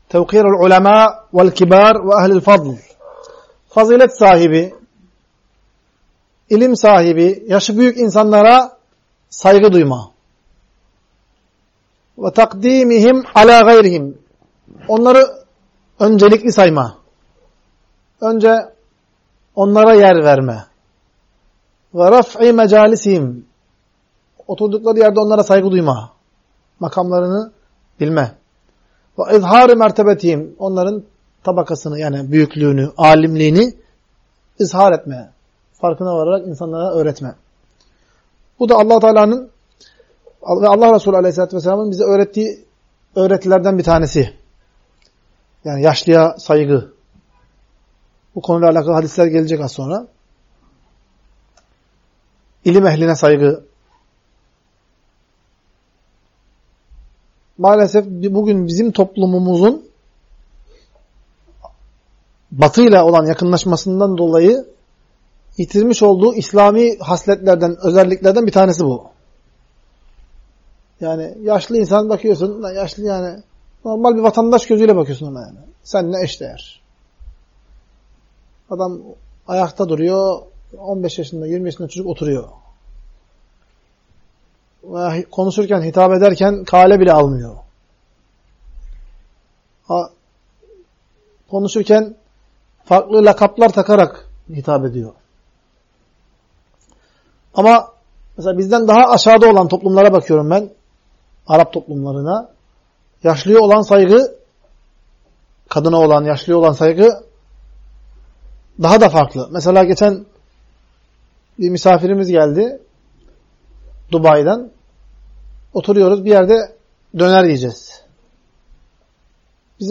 Ders - 44. Bölüm - Alimlere, Büyüklere Ve Fazilet Sahibi Kişilere Saygı Göstermek Onları Başkalarına Üstün Tutmak, Kıymetlerini Bilmek Ve Onlara Değer Vermek - Taybe İlim